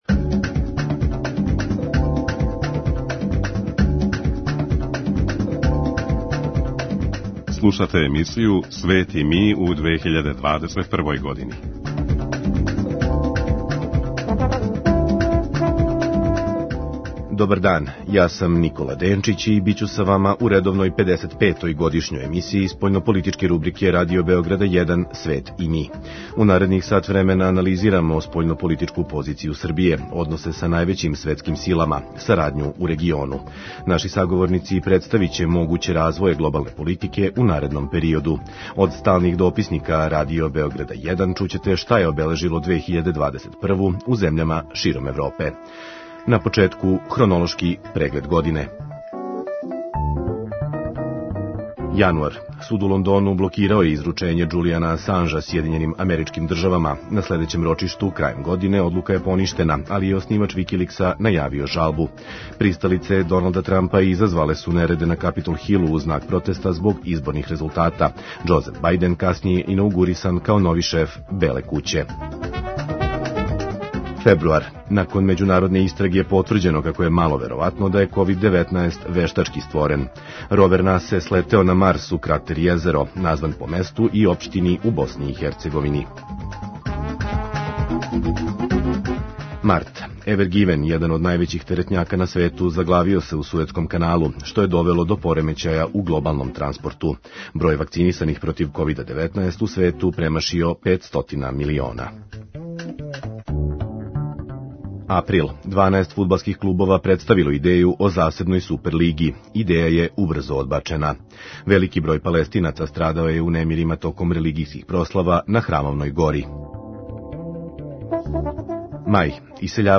Анализирамо спољнополитичку позицију Србије, односе са највећим светским силама, сарадњу у региону. Наши саговорници представиће могуће развоје глобалне политике у наредном периоду. Од сталних дописника Радио Београда 1 чућете шта је обележило 2021-ву у земљама широм Европе.